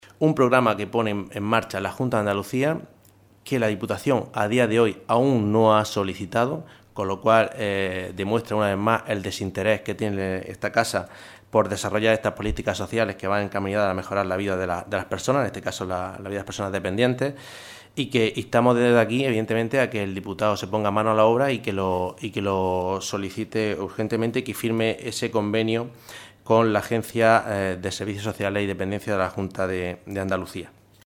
Por ejemplo, ha dicho en la rueda de prensa, bajo el epígrafe ‘Asistencias, visitas y reuniones’ en el pueblo de Serón –del que Lorenzo es alcalde- se ha destinado en 2016 un gasto de 3.300 euros.